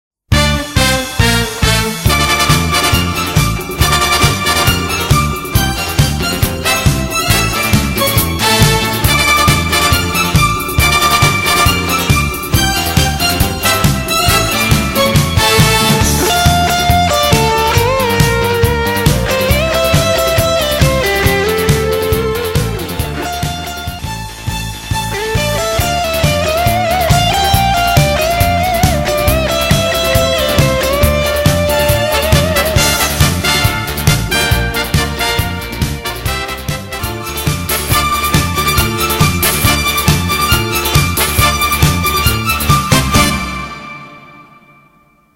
難易度 分類 並足１２６ 時間 ３分２６秒
編成内容 大太鼓、中太鼓、小太鼓、シンバル、トリオ 作成No ２７１